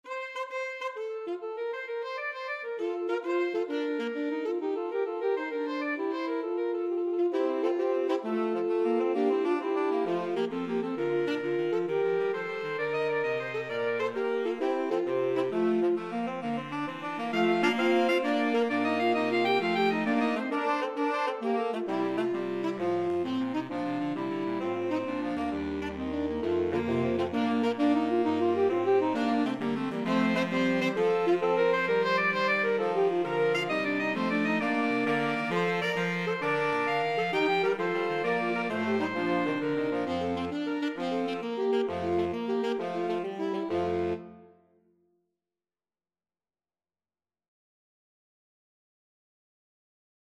Voicing: Saxophone Quartet (AATB)